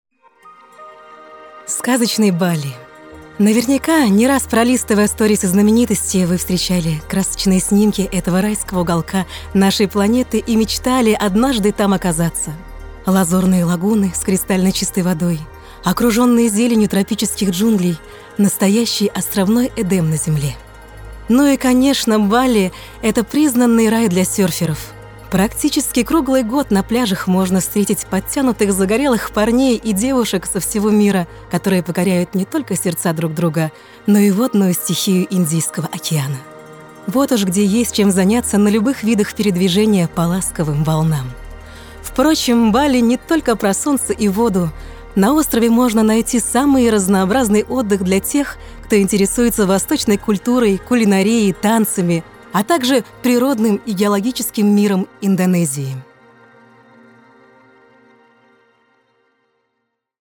Обладательница лирико-драматического сопрано с диапазон более четырёх октав.